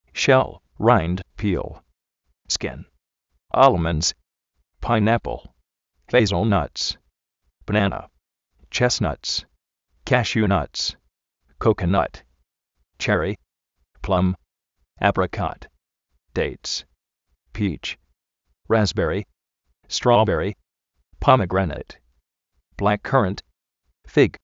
Vocabulario en ingles, diccionarios de ingles sonoros, con sonido, parlantes, curso de ingles gratis
páin-ápl
póme-gréneit